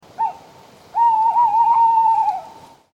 Der Waldkauz
Sein bekannter Ruf, das lang gezogene „Huu-hu-huhuhuhuu“, ertönt vor allem im Herbst und Spätwinter, wenn Waldkäuze balzen oder ihre Reviere markieren.
Waldkauz_audio.mp3